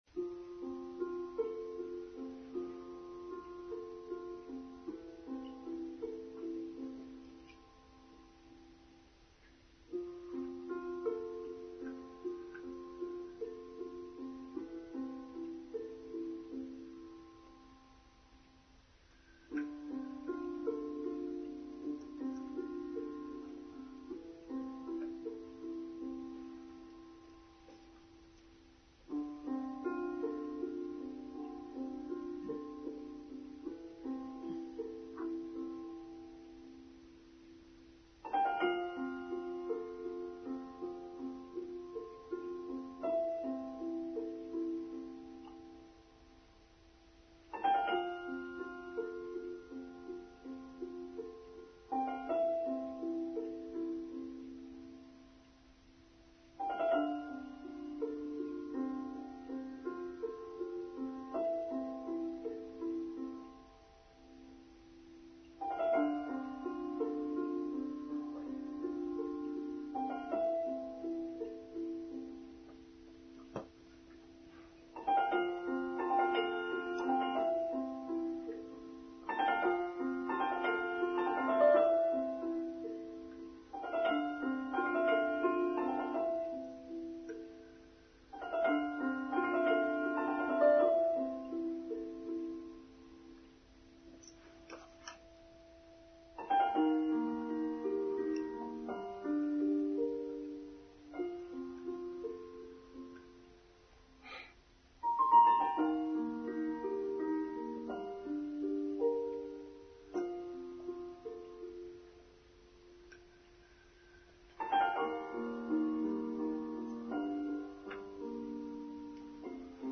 Beltane: Online Service for Sunday 5th May 2024